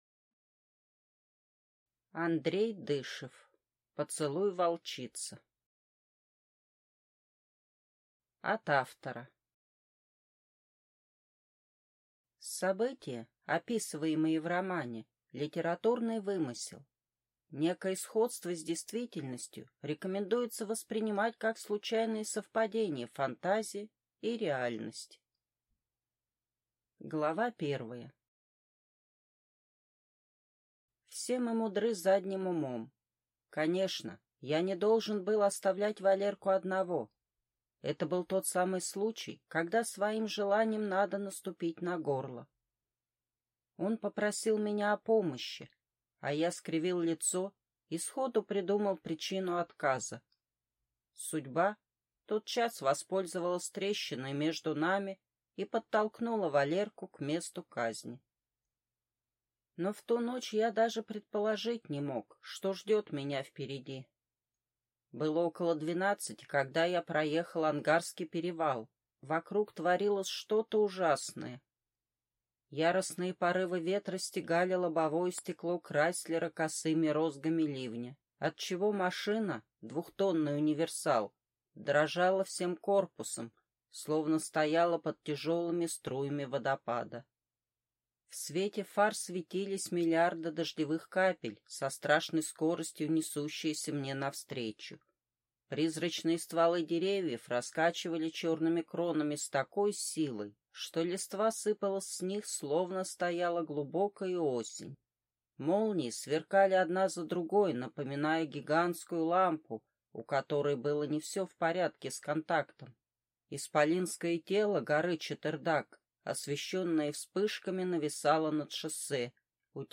Аудиокнига Поцелуй волчицы | Библиотека аудиокниг